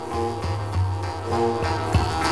fragment mondharpmuziek van deze CD
.wav 727 Kb - stereo - 22 Khz - 16 sec